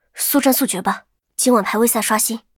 尘白禁区_安卡希雅辉夜语音_开始1.mp3